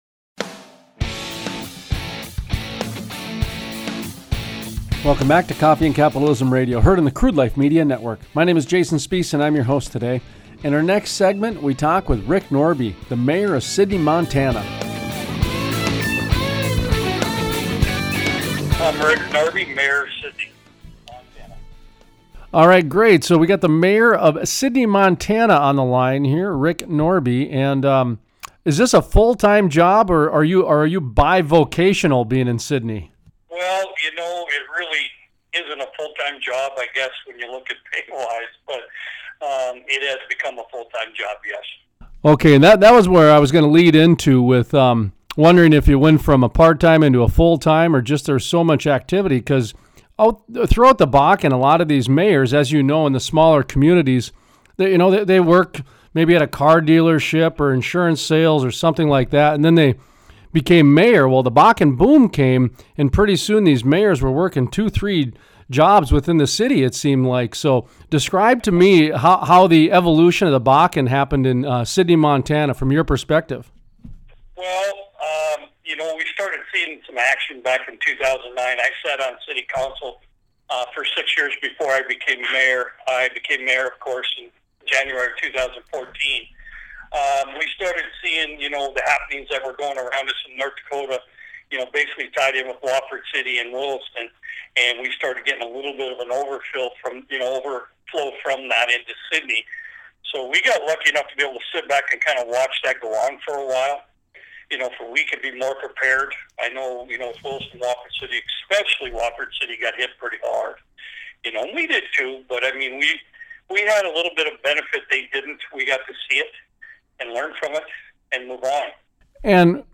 Interviews: Rick Norby, Mayor, Sidney, MT Talks about how the Bakken has impacted their city, what their future looks like and the potential consequences to the community if the Little Yellowstone dam is removed.